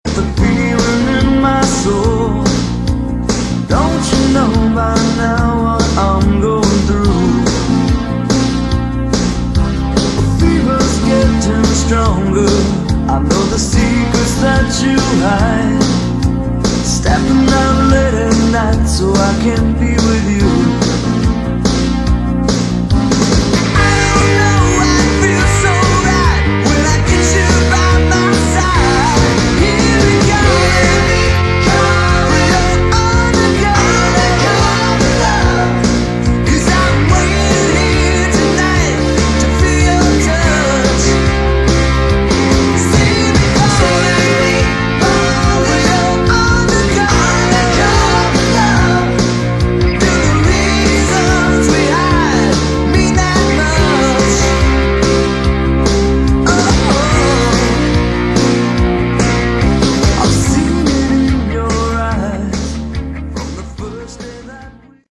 Category: AOR / Melodic Rock
Guitar, Lead Vocals
Bass, Vocals
Drums
Keyboards